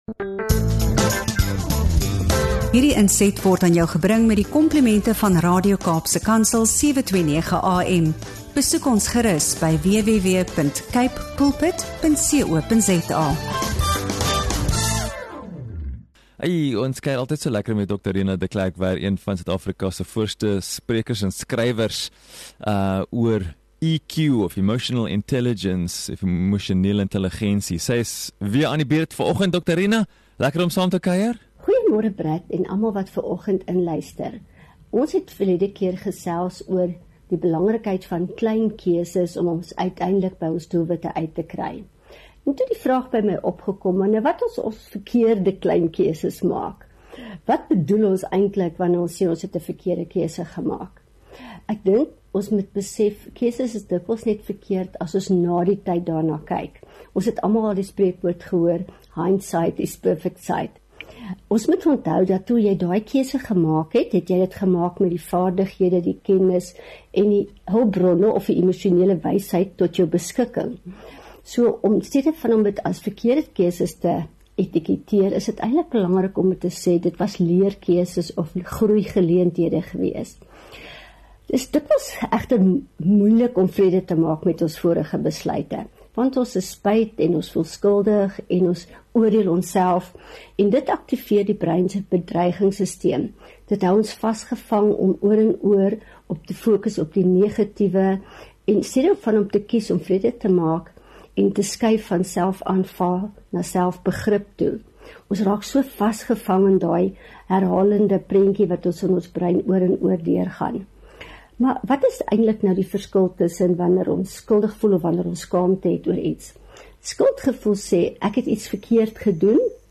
GET UP & GO BREAKFAST - INTERVIEW SPECIALS